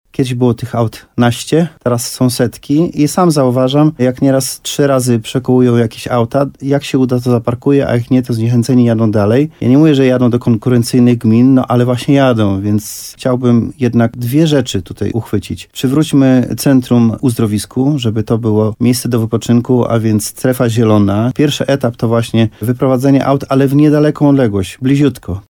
Parking poziomowy to jeden z pomysłów na rozwiązanie problemu z brakiem miejsc postojowych w Piwnicznej-Zdroju. – Jeśli taka inwestycja by się udała, można będzie usunąć samochody z płyty rynku, która będzie służyć w większej skali turystom – mówi burmistrz uzdrowiska, Dariusz Chorużyk.